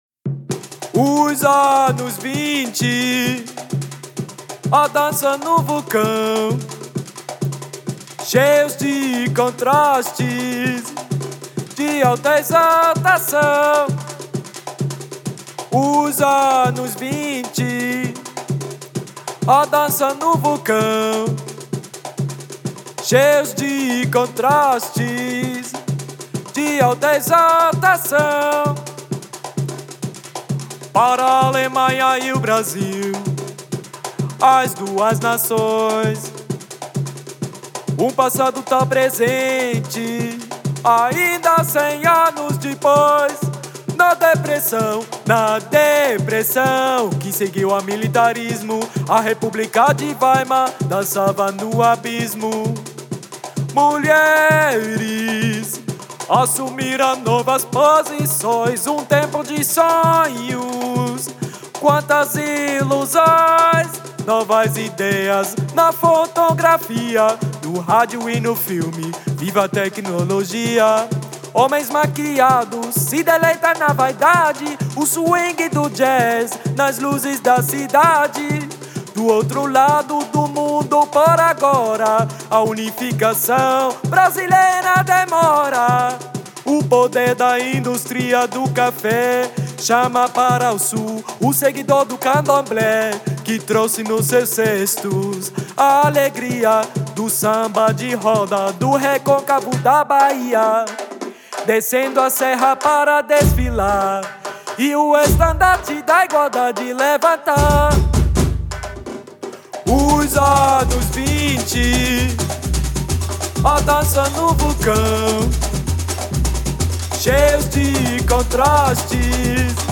The song, composed especially for the Carnival of Cultures, tells in the Brazilian carnival tradition of jazz nights in Berlin, the first carnival parades in Rio and the oppression and exploitation of the indigenous peoples of Brazil.
The music, rhythm and choreography radiate the pure energy of life.